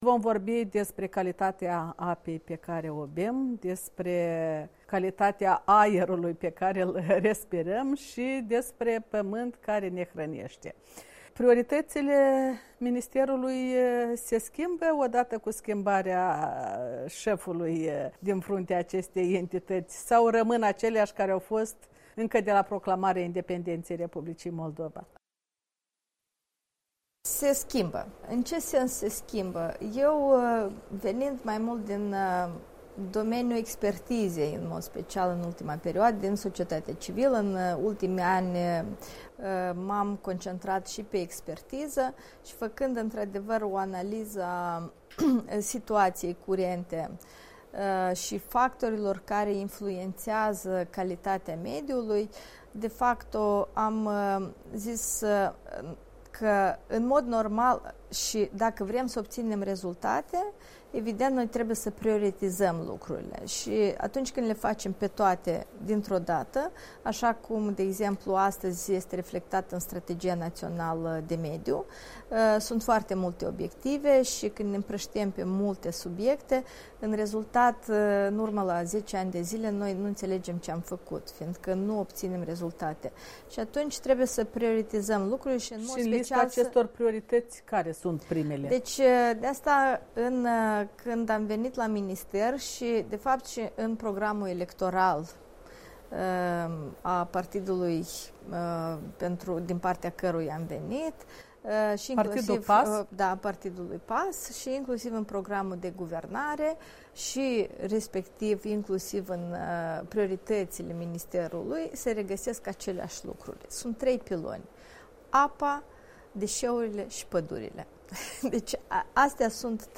Intervu cu ministrul mediului, Iuliana Cantaragiu